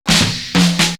Break 211.wav